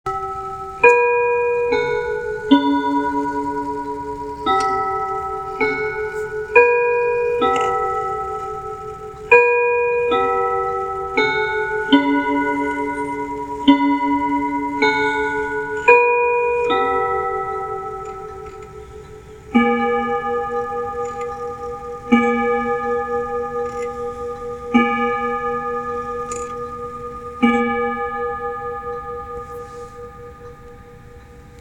Ansonia Clock Co. “Banjo No. 3”, ca. 1924.  Ansonia made three banjo models that used this “beveled chipped glass” in the throat and lower door; this is the only model with Westminster chimes on the quarter-hours, played on “Melodious True-toned Rods” – and they sound great!  It counts the hours on a fifth rod.
Here is an audio recording of the chime and strike: